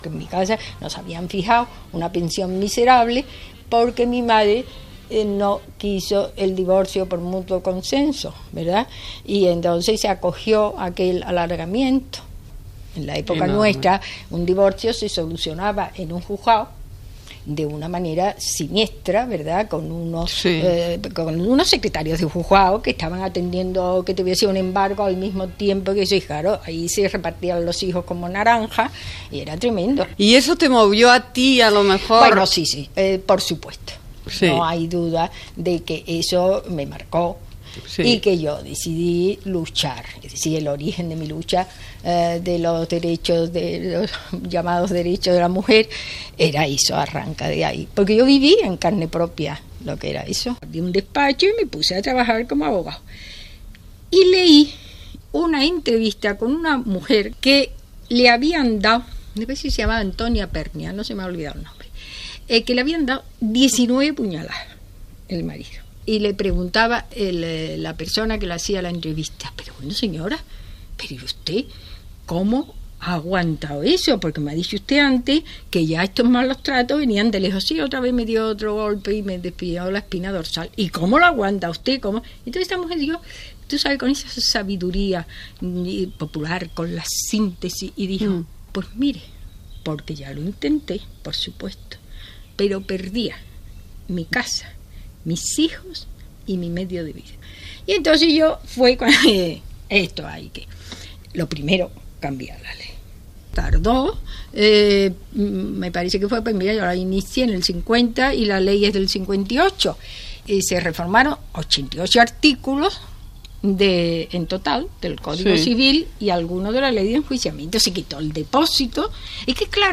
Entrevista a l'advocada Mercedes Fórmica, sobre la reforma del dret de família a l'any 1958, feta a casa seva